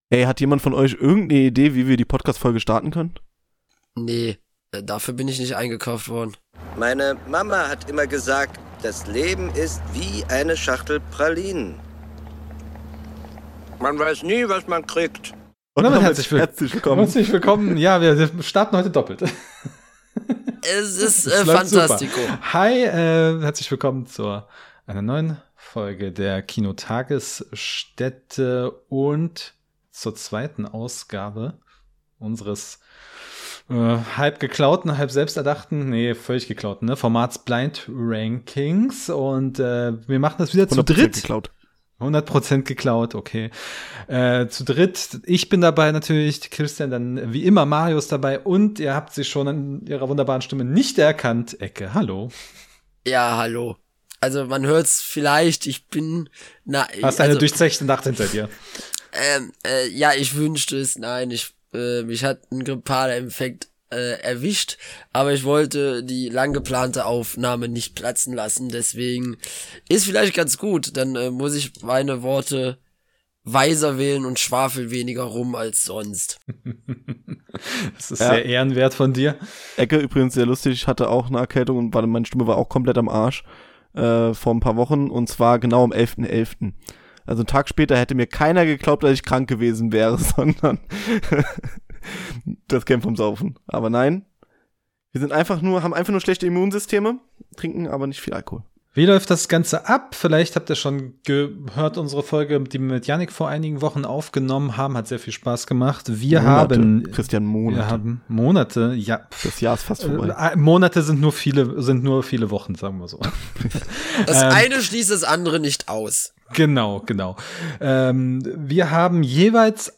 Blind Rankings zu Oscar-Gewinnern, Tom Hanks & Weihnachtsfilmen | Special-Talk ~ Die Kinotagesstätte Podcast
Drei Podcaster mal wieder im Blindflug: Wir blindranken mal wieder!